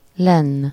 Ääntäminen
Synonyymit lent Ääntäminen Tuntematon aksentti: IPA: /ˈlɛnː/ Haettu sana löytyi näillä lähdekielillä: unkari Käännöksiä ei löytynyt valitulle kohdekielelle.